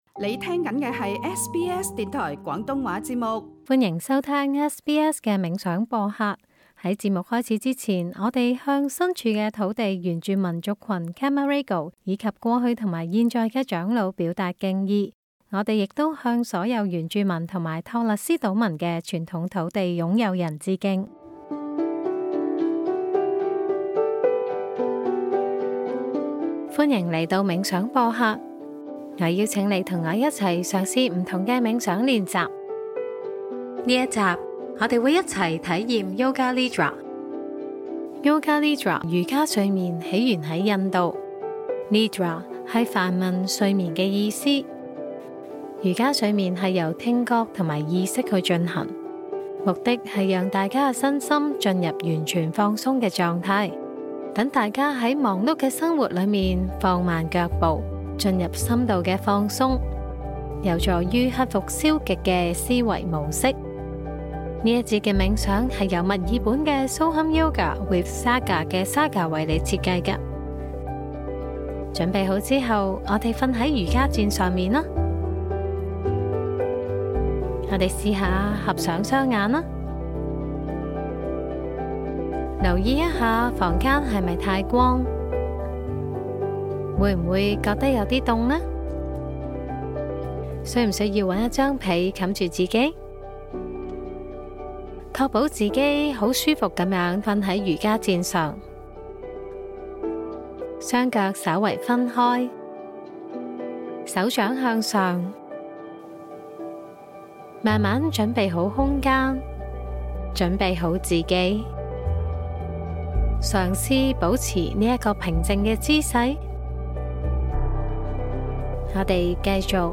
瑜伽睡眠是一種讓人進入「深度放鬆」的冥想，主要透過語音引導，讓身心進入完全放鬆的狀態，達到真正的休息。